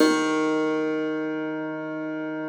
53k-pno07-D1.wav